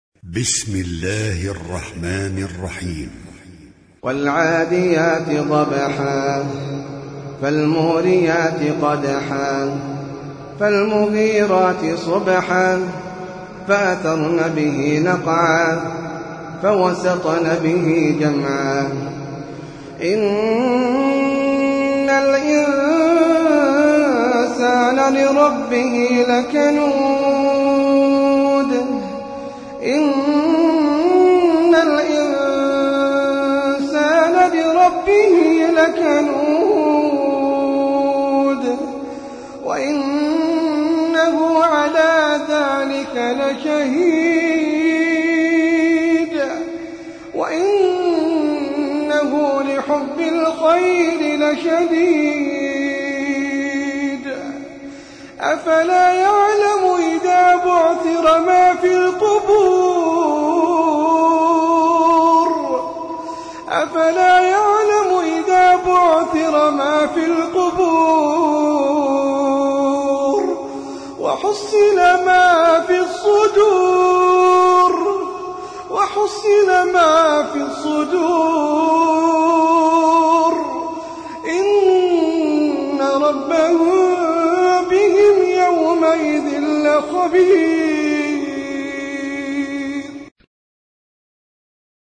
Sûrat Al-'adiyat (Those That Run) - Al-Mus'haf Al-Murattal
high quality